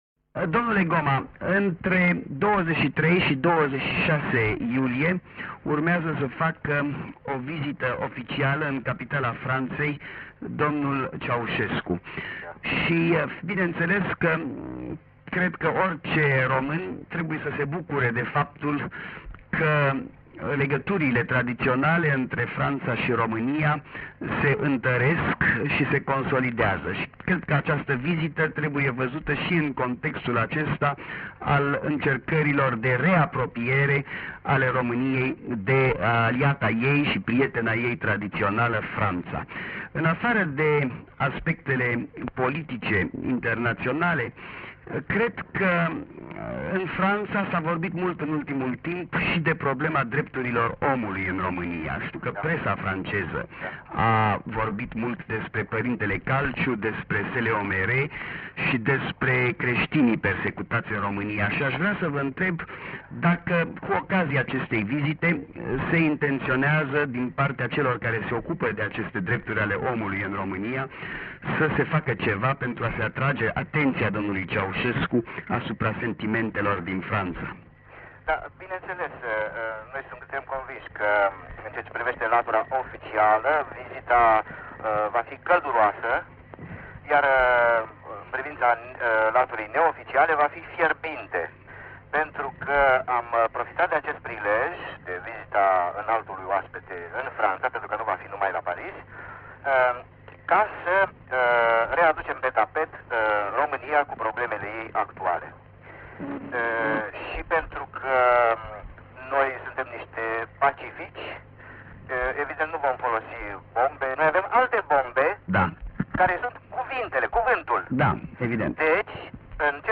Un interviu cu scriitorul disident în ajunul vizitei liderului de stat al României la Paris.